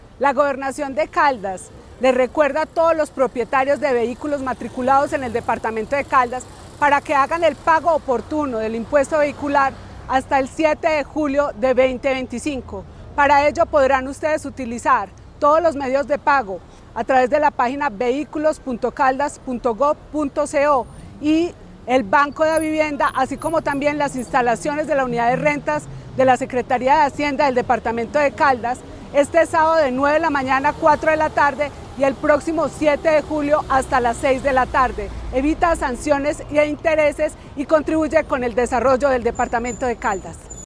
Descargar Video Descargar Audio Sandra Marcela Osorio Castellanos, secretaria (e) de Hacienda de Caldas.